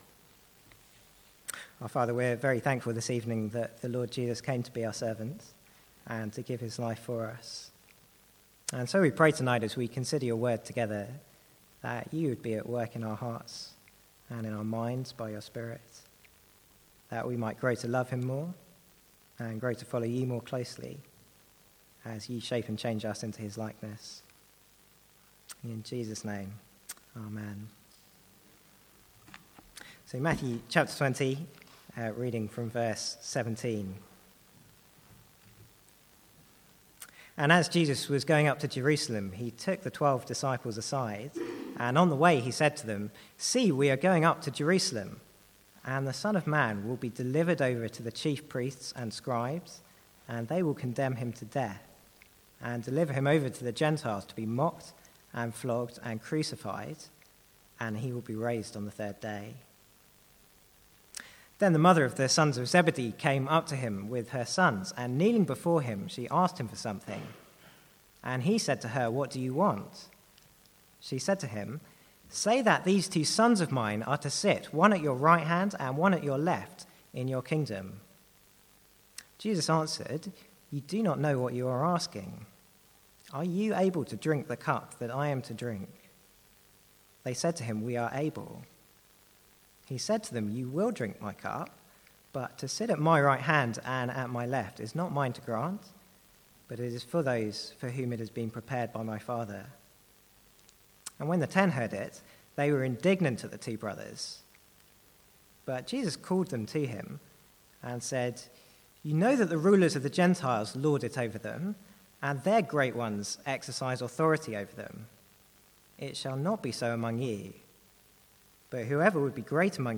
Sermons | St Andrews Free Church
From the Sunday evening series in Matthew.